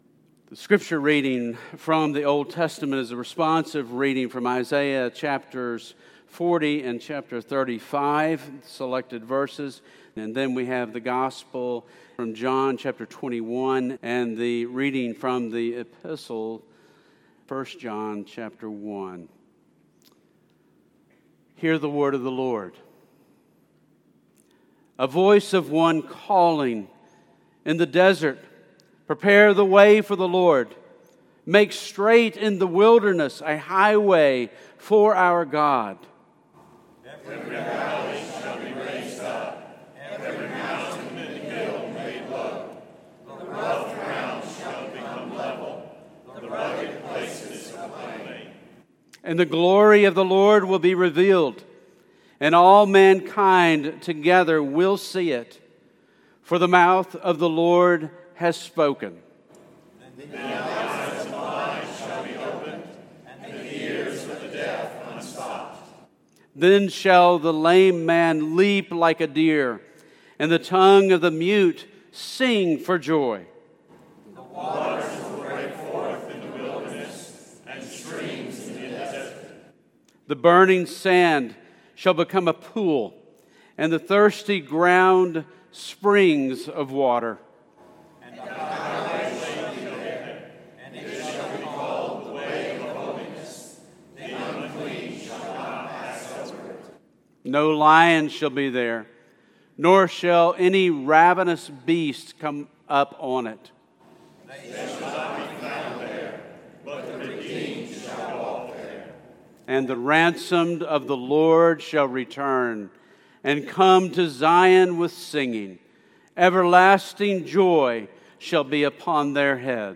This sermon is part of the following series: